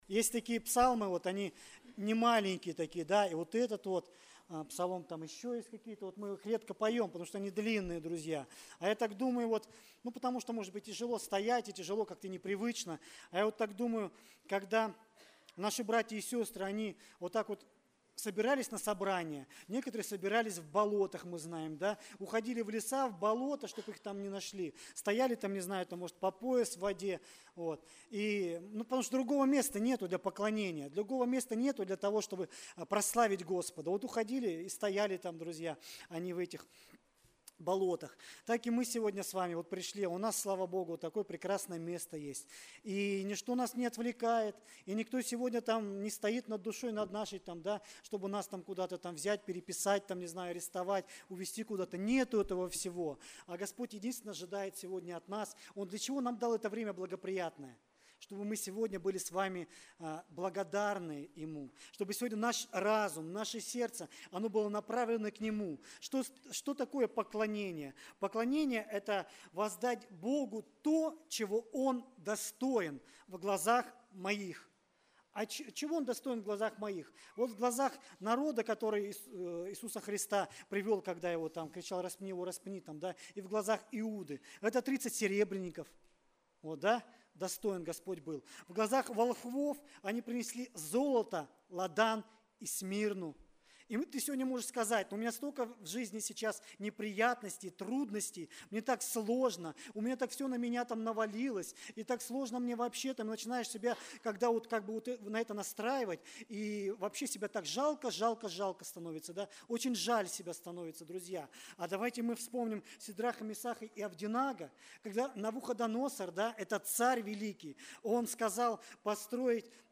Богослужение 25.08.2024
Проповедь